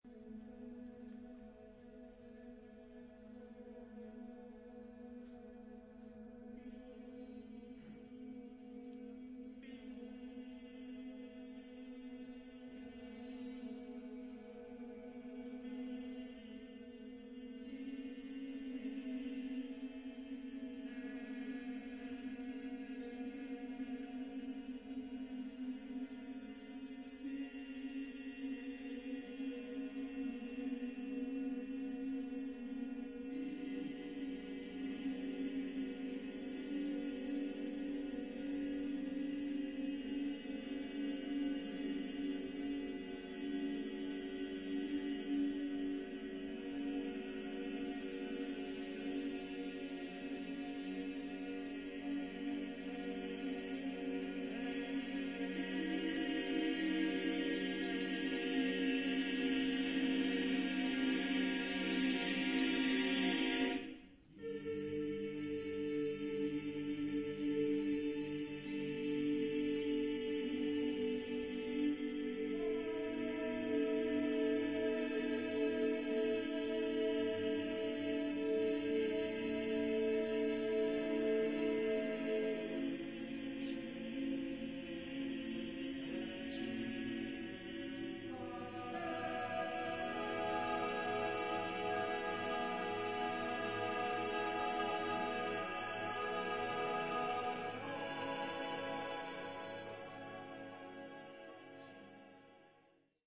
Genre-Style-Form: Secular ; Contemporary
Mood of the piece: slow
Type of Choir: TTTBBB  (6 men voices )
Tonality: atonal